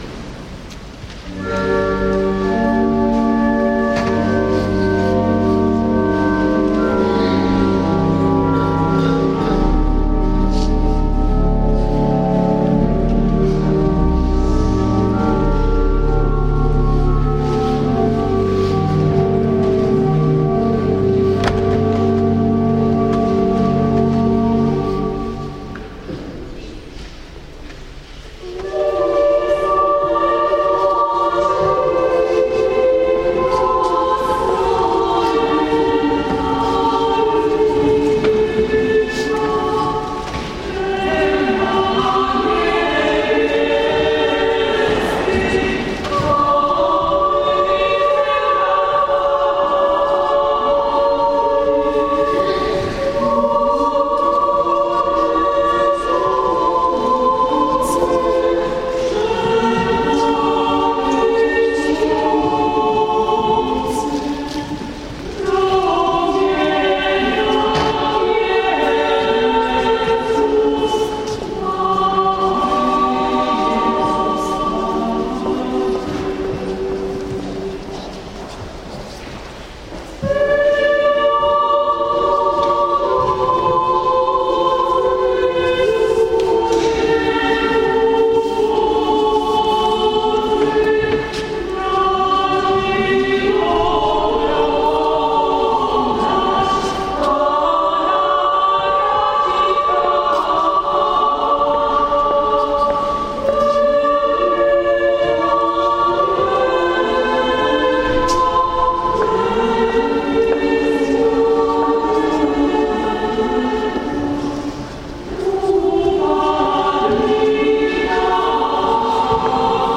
Oto materiał nagrany na ostatnim zjeździe poprzedniej edycji Kursu.
Wielogłosowa wersja znanej kolędy Mizerna cicha – wykonana na styczniowym zjeździe.